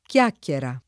vai all'elenco alfabetico delle voci ingrandisci il carattere 100% rimpicciolisci il carattere stampa invia tramite posta elettronica codividi su Facebook chiacchiera [ k L# kk L era ] s. f. — non chiacchera — sim. il cogn.